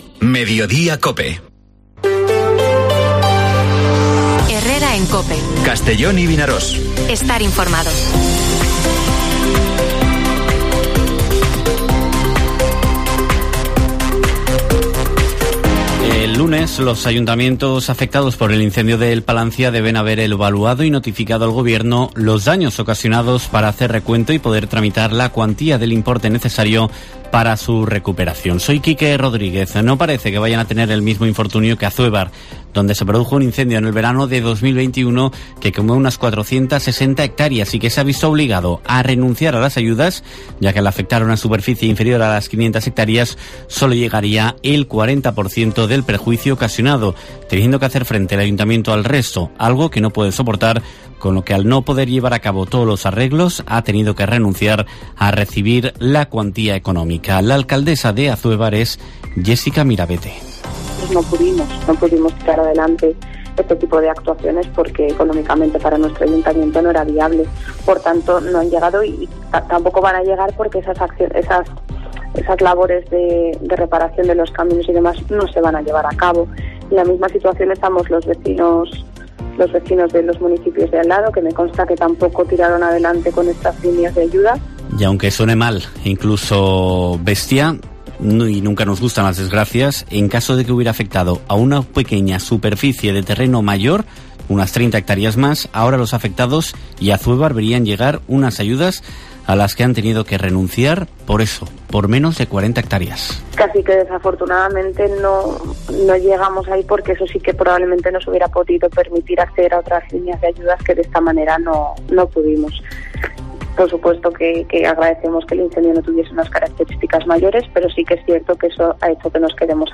Informativo Mediodía COPE en la provincia de Castellón (23/09/2022)